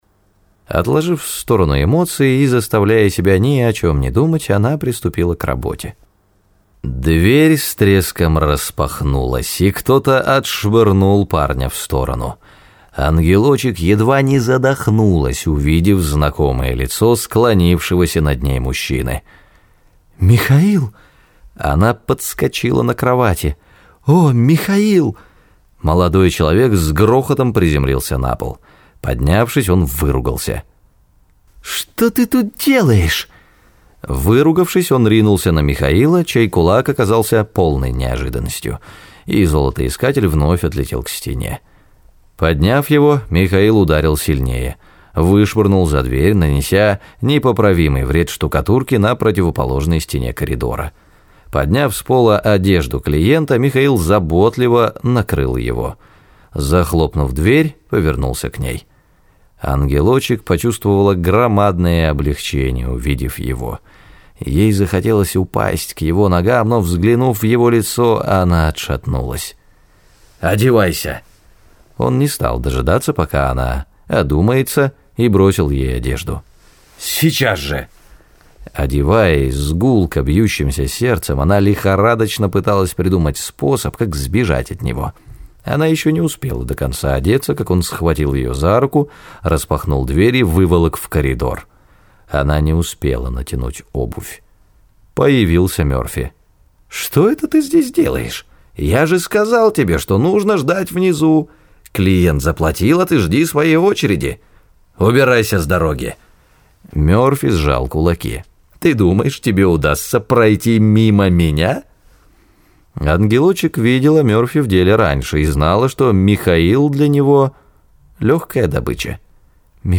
Раздел: Аудиокниги